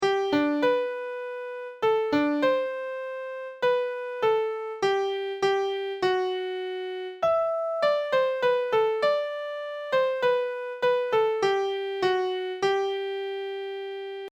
Canon for Equal Voices